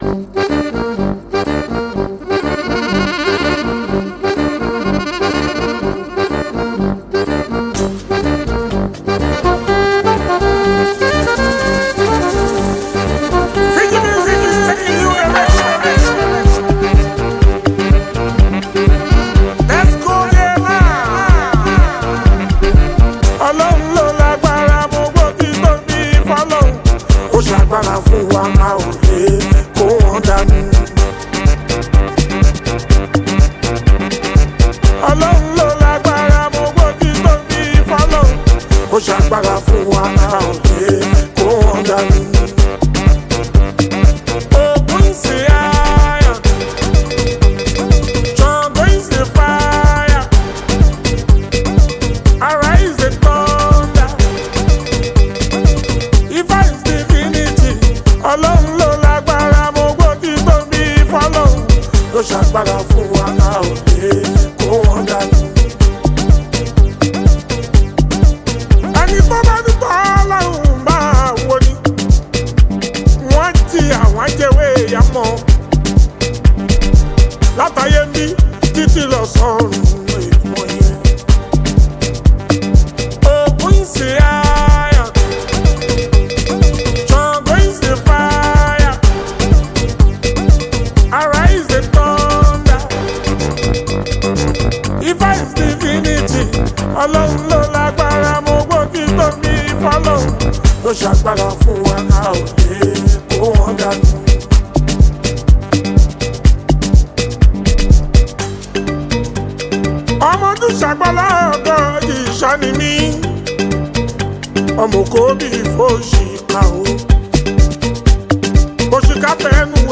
Fuji, Highlife
Nigerian Yoruba Fuji track